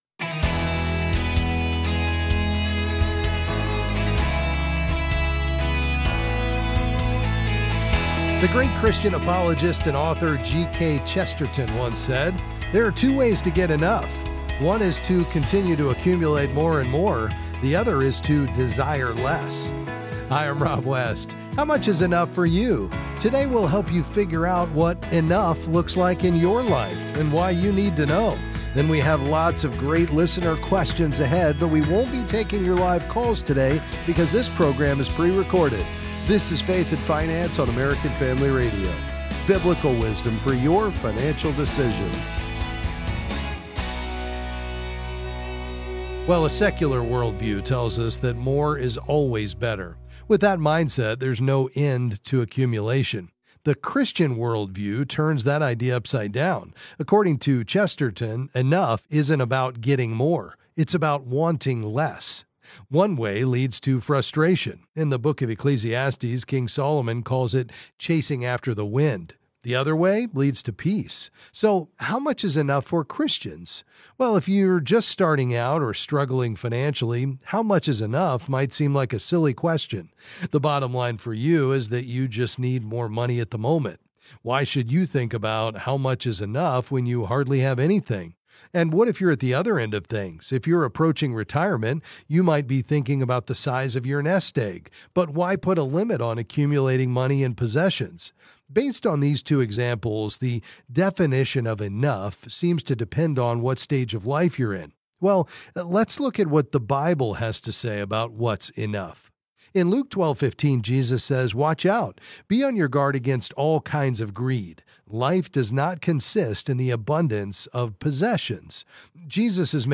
Then he’ll take some calls and answer various financial questions.